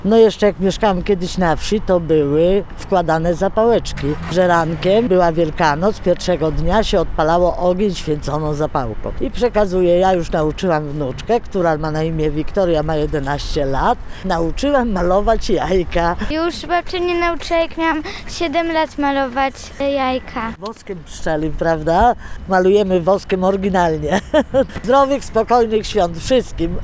Ełczanie przypominają również zapomniane już tradycje związane ze święceniem pokarmów. Zgodnie podkreślają, że ważne jest przekazywanie ich młodszemu pokoleniu.